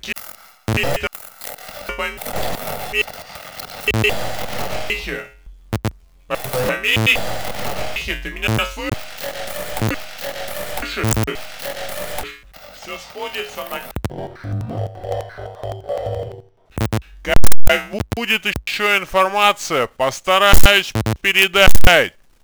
В аудиофайле необходимо выделить необходимую область (после слов "все сходится на") и увеличить частотный спектр участка.